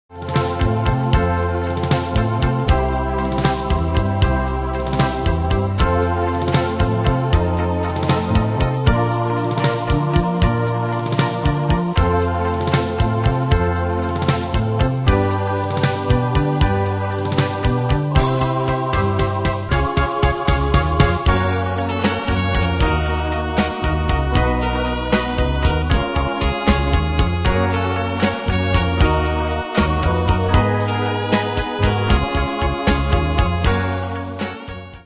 Style: Retro Année/Year: 1965 Tempo: 155 Durée/Time: 3.01
Danse/Dance: Ballade Cat Id.
Pro Backing Tracks